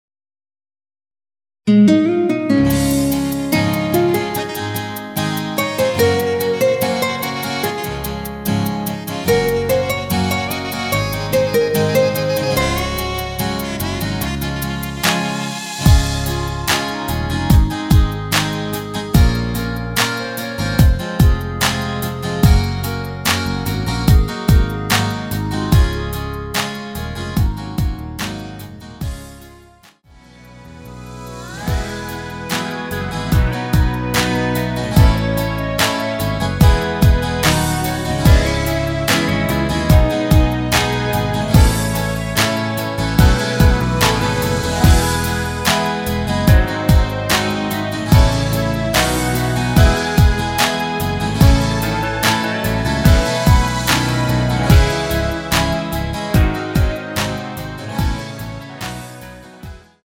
원키에서(-3)내린 멜로디 포함된 MR입니다.
Db
앞부분30초, 뒷부분30초씩 편집해서 올려 드리고 있습니다.
(멜로디 MR)은 가이드 멜로디가 포함된 MR 입니다.